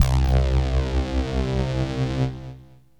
SYNTH LEADS-1 0008.wav